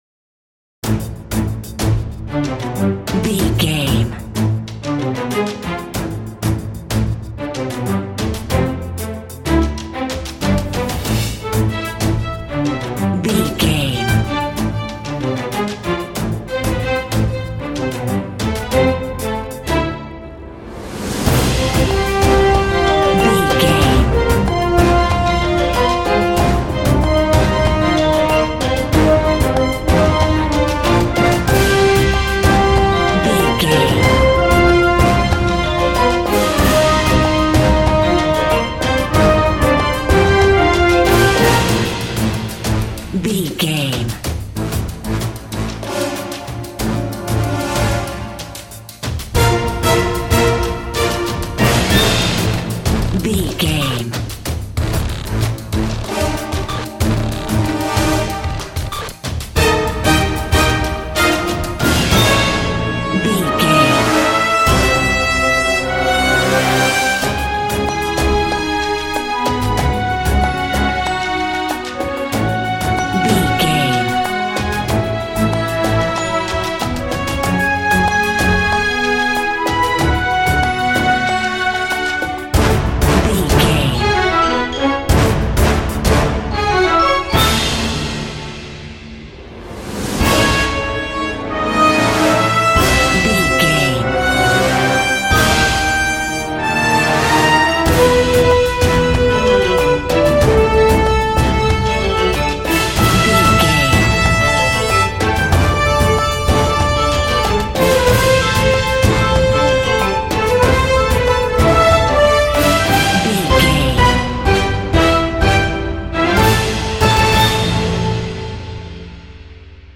Epic / Action
Fast paced
Aeolian/Minor
cinematic
suspense
orchestra
synthesizer
haunting
heroic